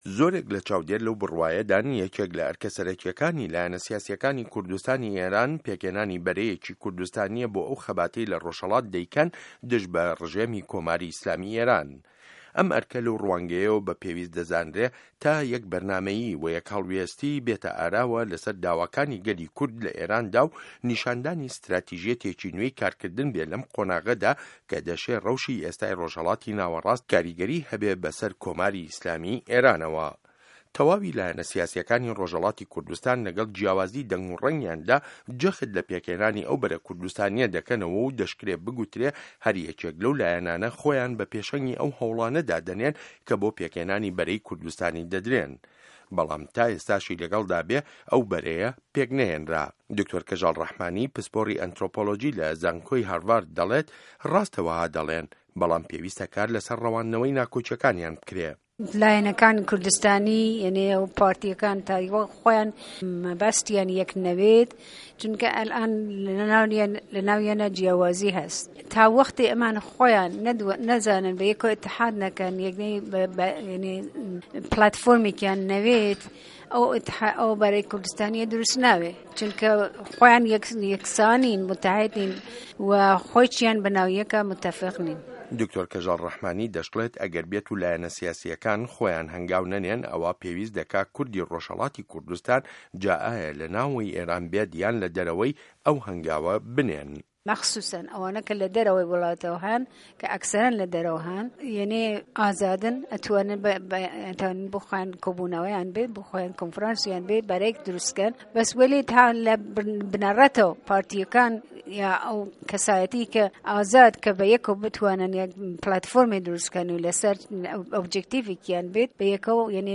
ڕاپۆرت